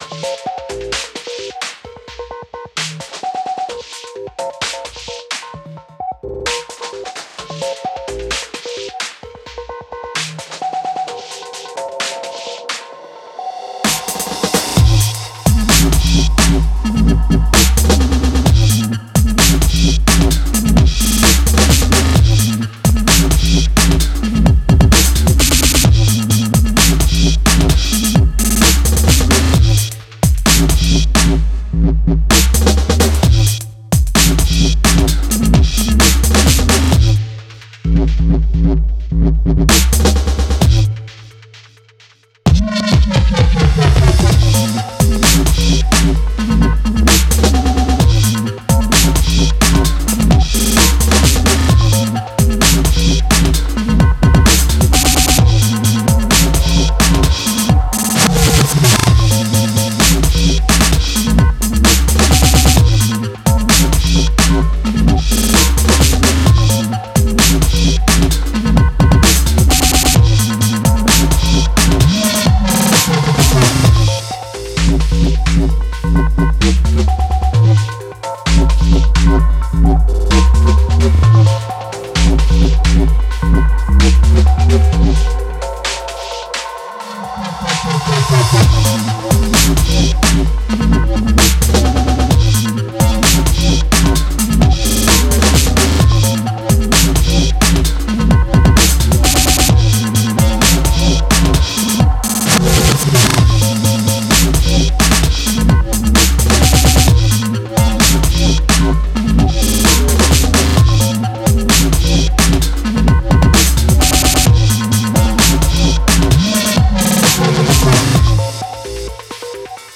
Data flows to a wobbly, glitched up beat.